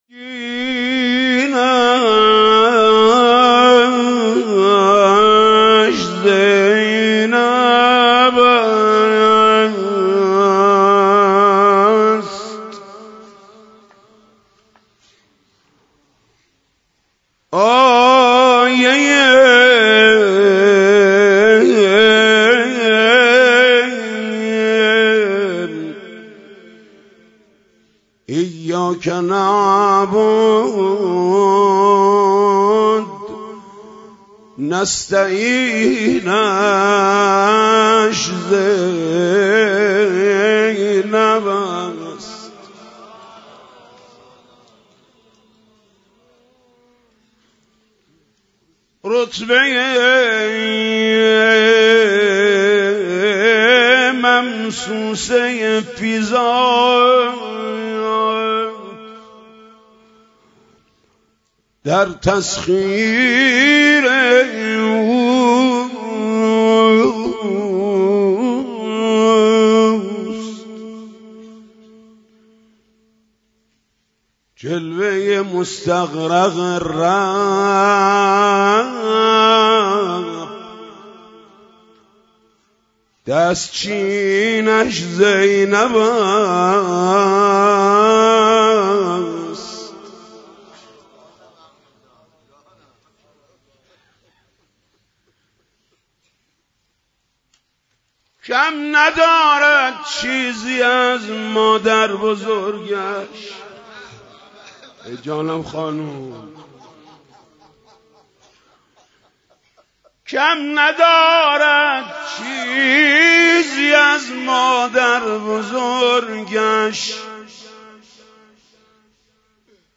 روضه شب پنجم محرم